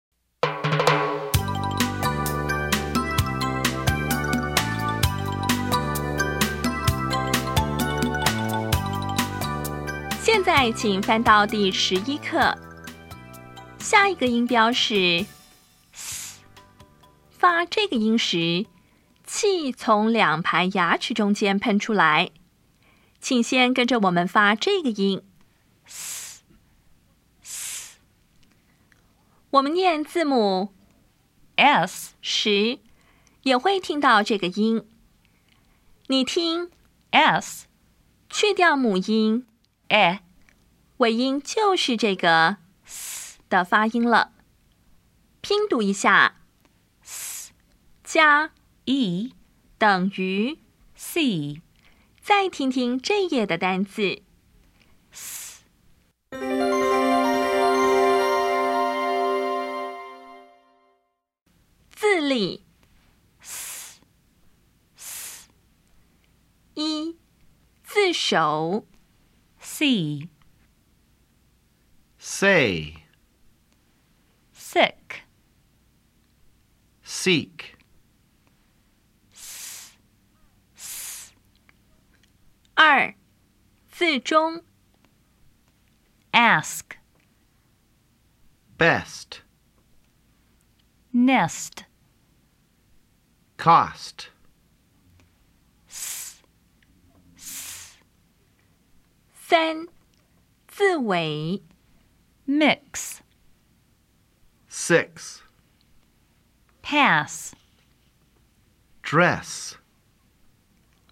当前位置：Home 英语教材 KK 音标发音 子音部分-1: 无声子音 [s]
音标讲解第十一课
[sɪk]